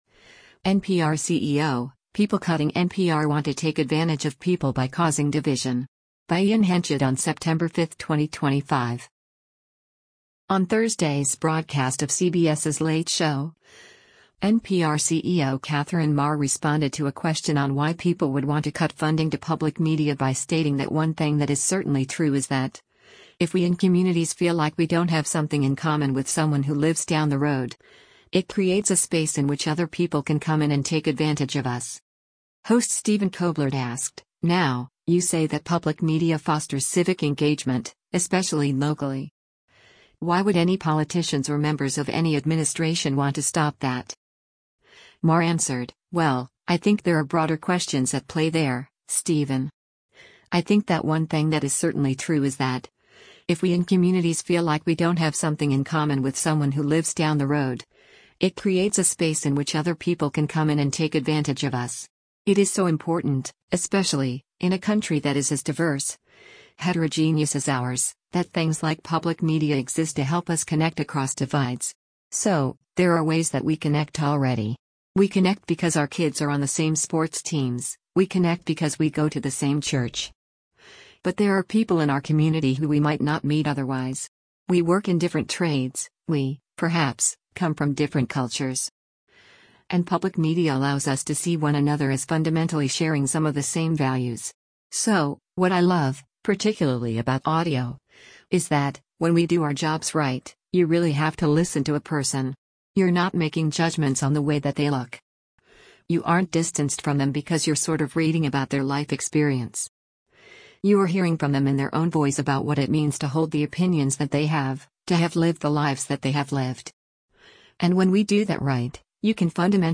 On Thursday’s broadcast of CBS’s “Late Show,” NPR CEO Katherine Maher responded to a question on why people would want to cut funding to public media by stating that “one thing that is certainly true is that, if we in communities feel like we don’t have something in common with someone who lives down the road, it creates a space in which other people can come in and take advantage of us.”